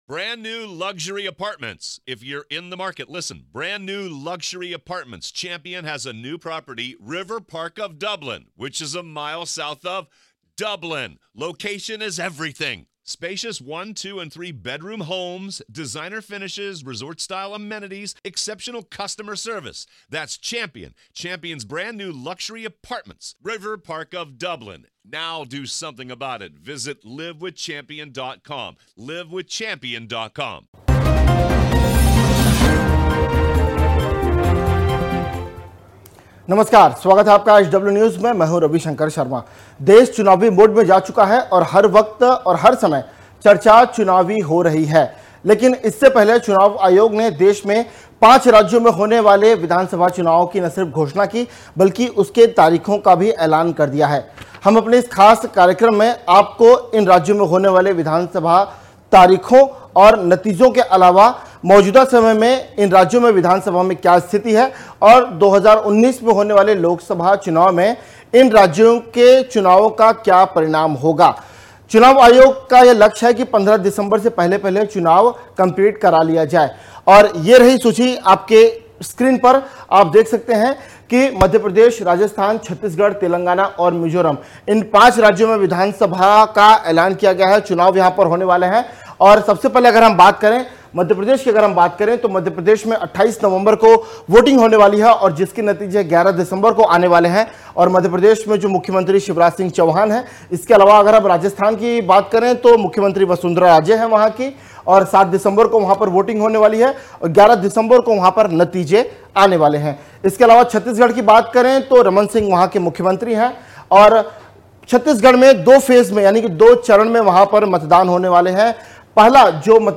न्यूज़ रिपोर्ट - News Report Hindi / मध्य प्रदेश, छत्तीसगढ़, राजस्थान, मिजोरम, तेलंगाना के विधानसभा चुनाव में कौन जीतेगा?